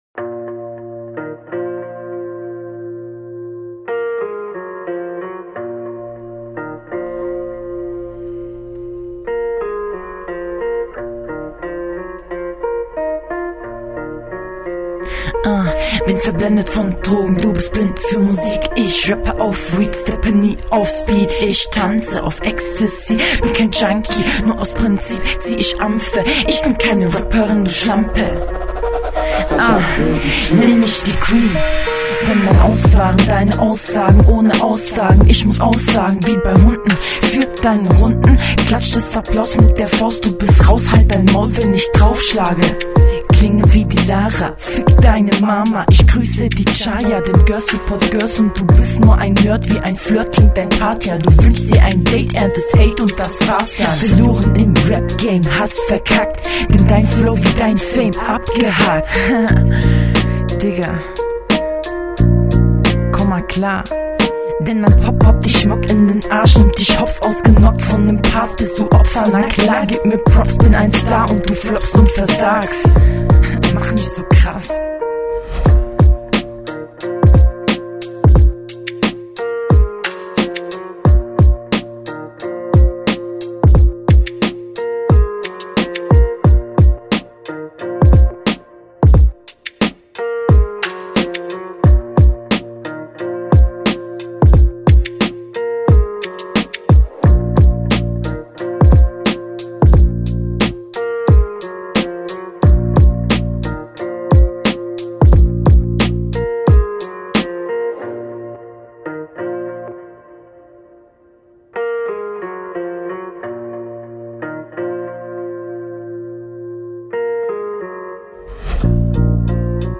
Flow: Klingt flüssiger und solider als beim Gegner.